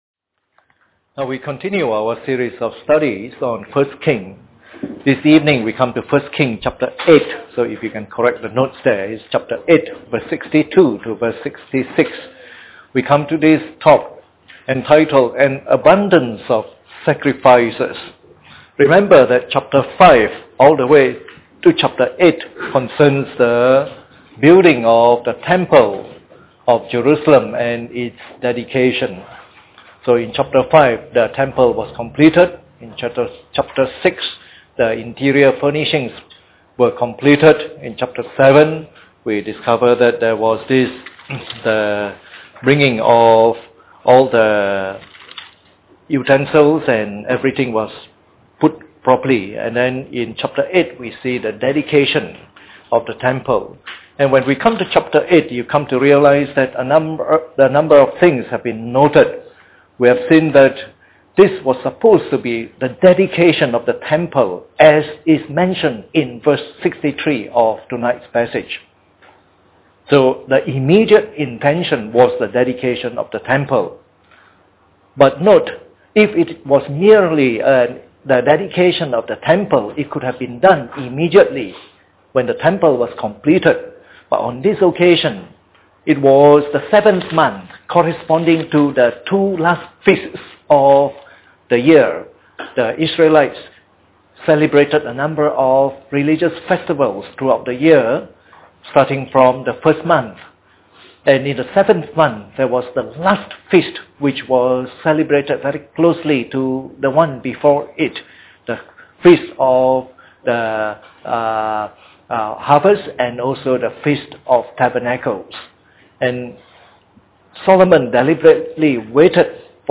Part of the “1 Kings” message series delivered during the Bible Study sessions.